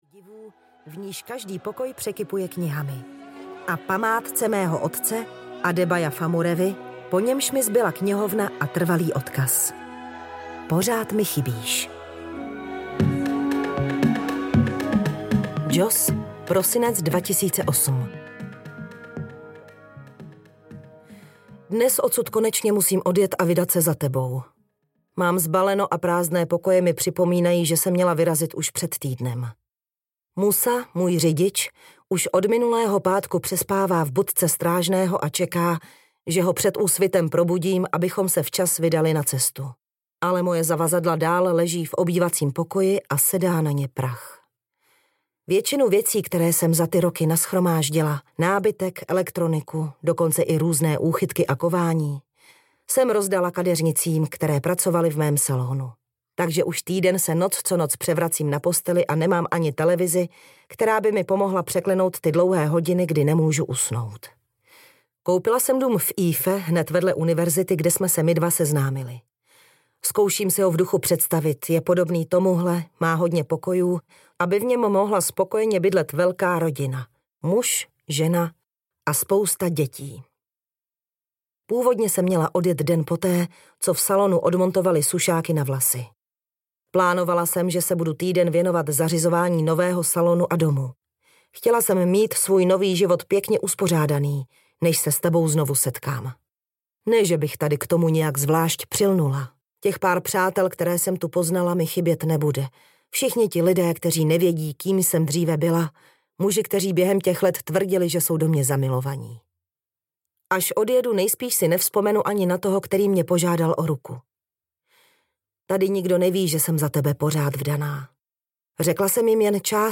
Zůstaň se mnou audiokniha
Ukázka z knihy